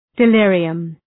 Προφορά
{dı’lırıəm}